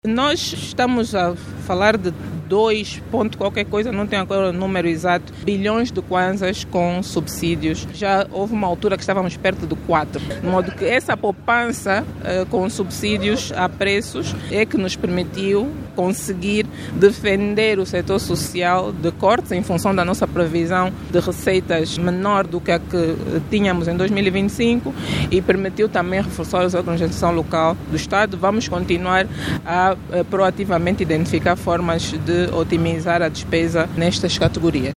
A informação foi avançada ontem pela ministra das Finanças, à margem da reunião plenária da Assembleia Nacional, que aprovou, na globalidade, o Orçamento Geral do Estado para 2026, com votos favoráveis do MPLA, PRS, FNLA e PHA, enquanto a UNITA votou contra.